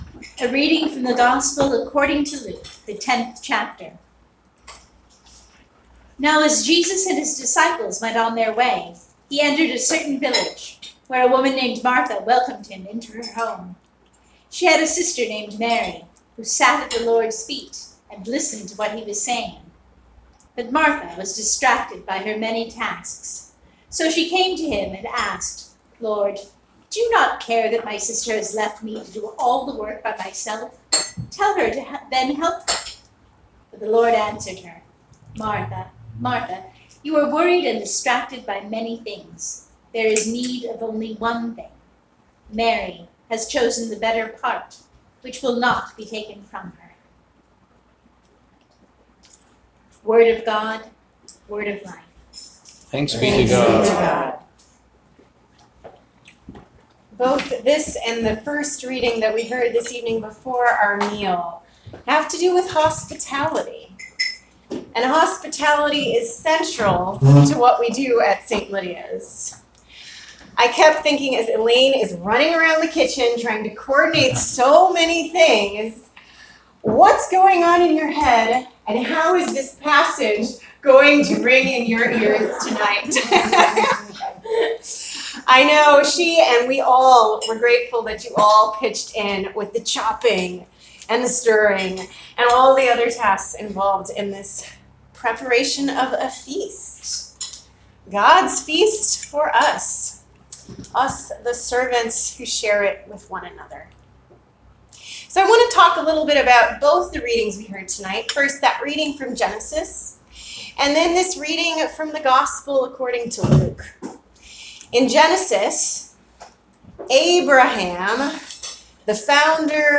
July 21, 2019 Sermon